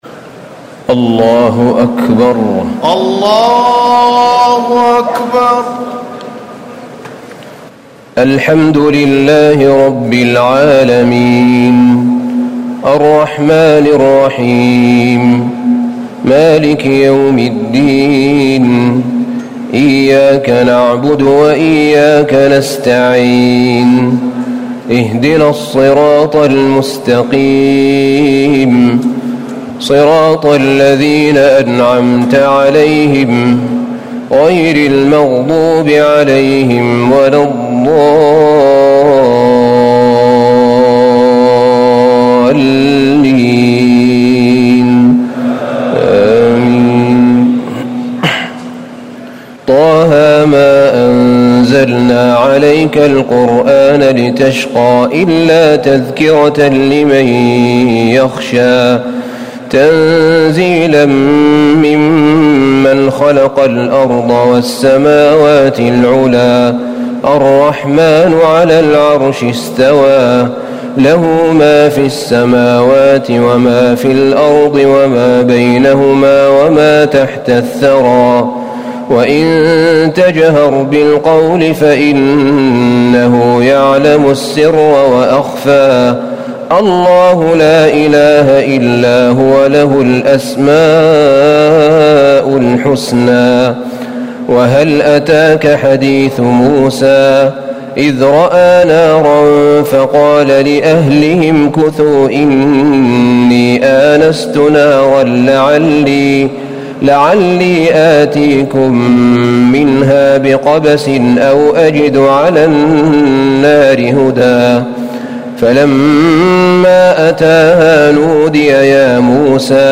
تراويح الليلة الخامسة عشر رمضان 1439هـ سورة طه كاملة Taraweeh 15 st night Ramadan 1439H from Surah Taa-Haa > تراويح الحرم النبوي عام 1439 🕌 > التراويح - تلاوات الحرمين